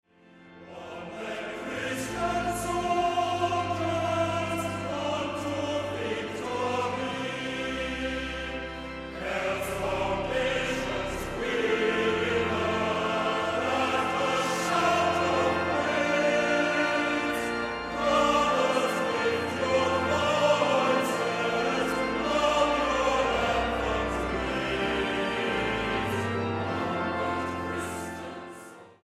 The Scottish Festival Singers - Favourite Hymns Vol 5
STYLE: Hymnody
This is a very well recorded album that shows the choir at its best, musically produced with a high recording quality.